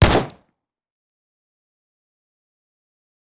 rifle_shot2.wav